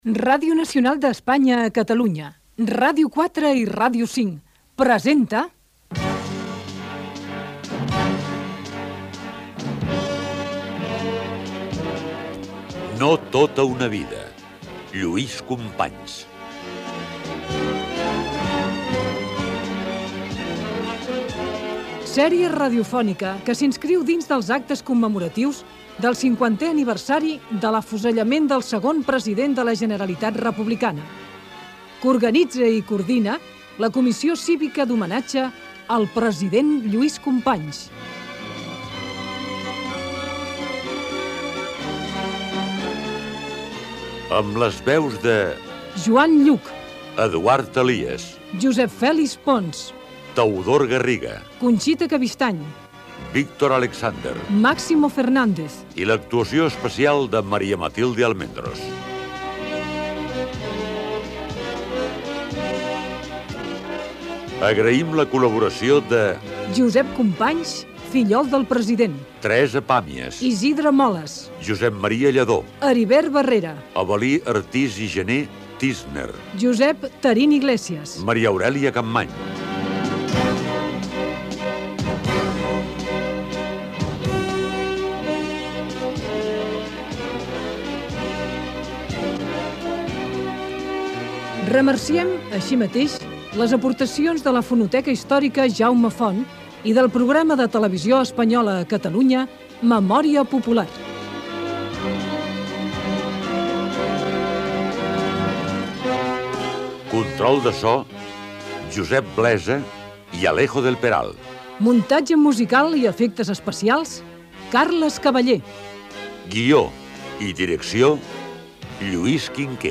Careta del programa amb els noms de l'equip i dels invitats. Espai dedicat a la vida del president de la Generalitat Lluís Companys